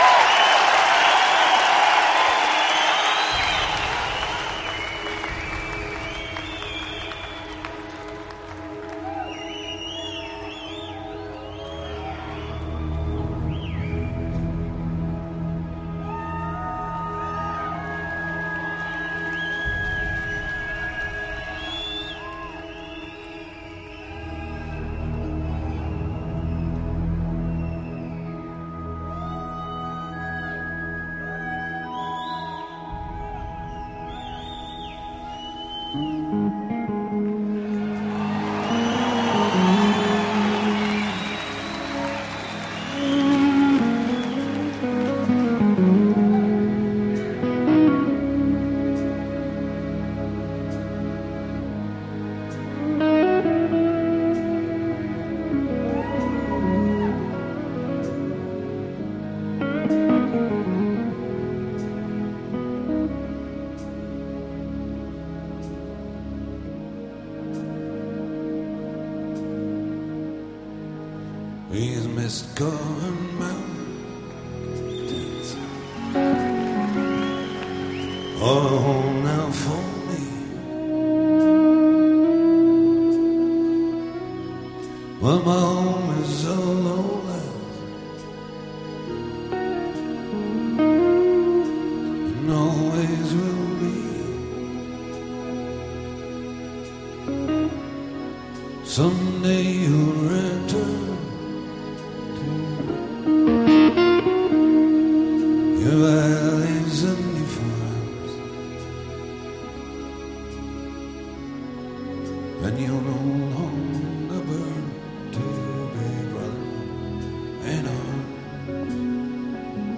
Live版本,Studio版本